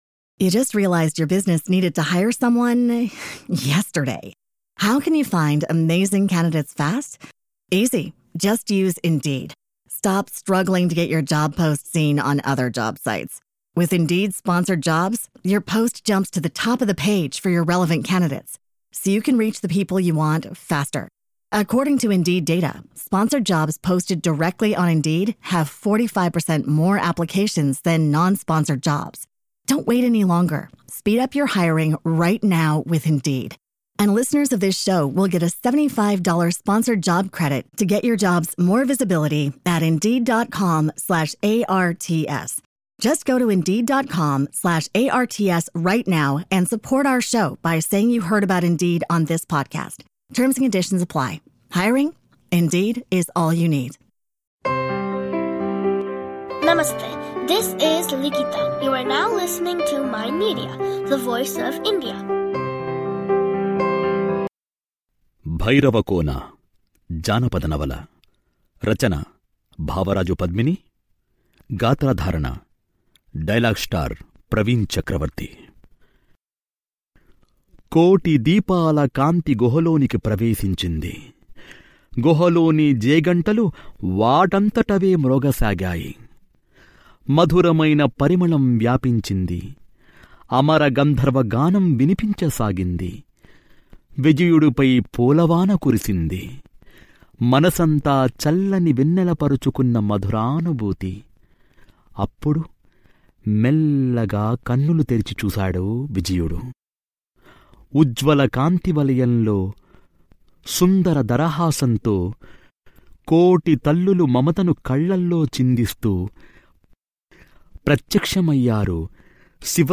Audio Books
Bhairavakona audio novel part 3.